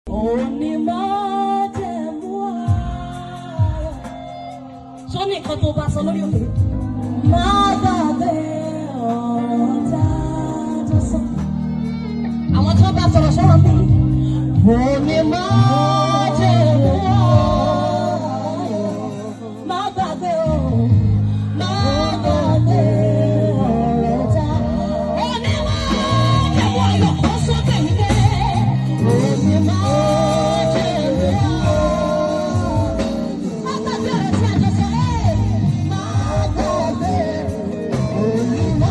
Yoruba Gospel Music
a powerful worship song to uplift and inspire you.